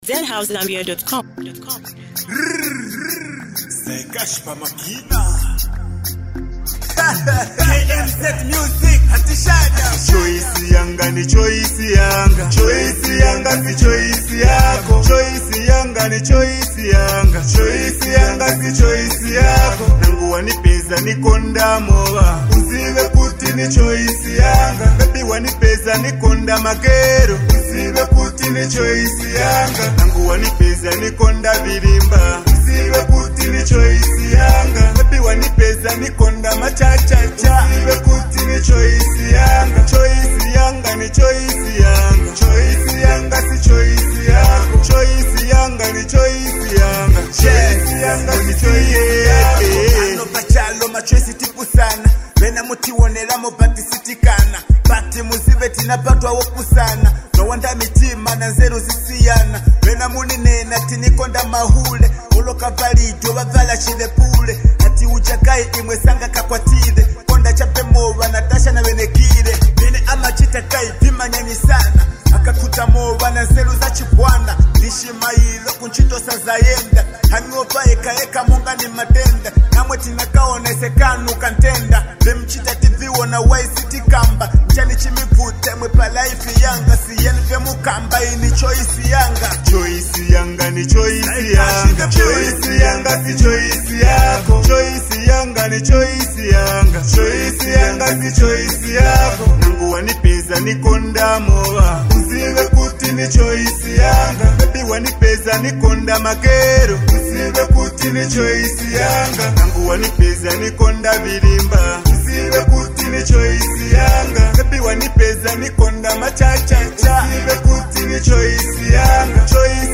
smooth production